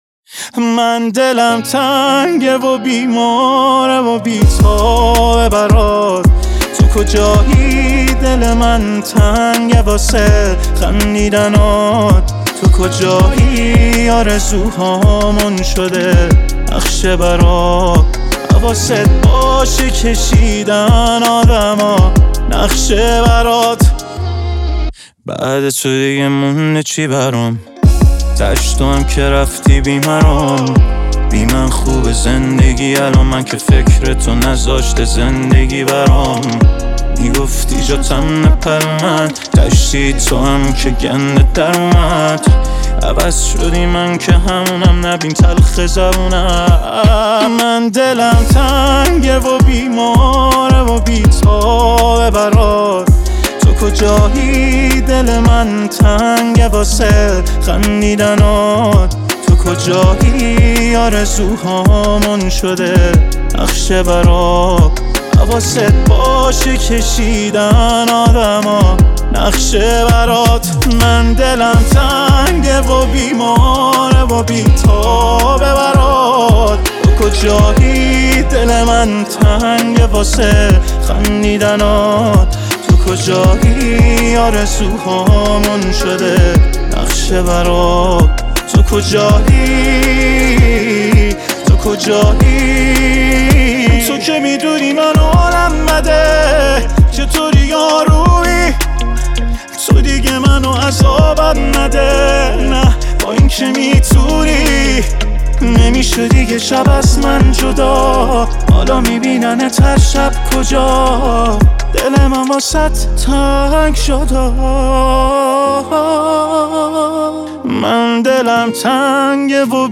موسیقی پاپ ایران
حال‌و‌هوایی عاشقانه و احساسی دارد.